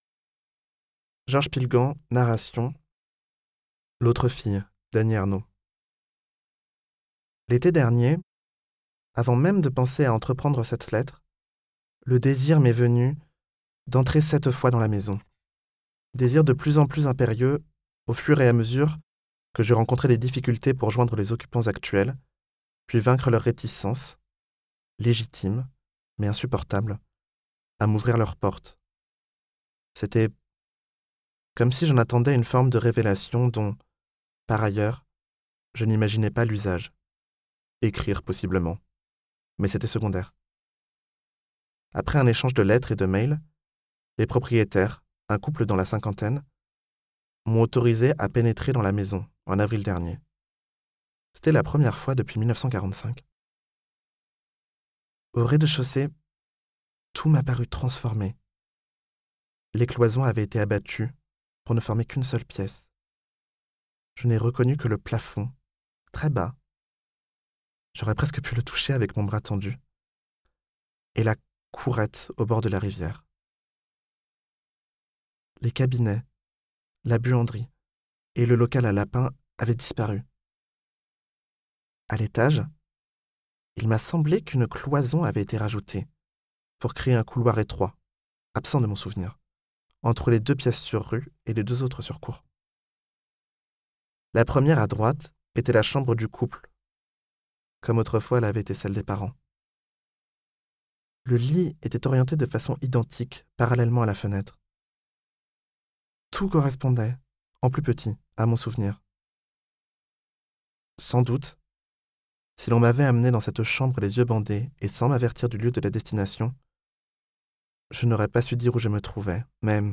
Lecture : L'autre Fille, d'Annie Ernaux (extrait)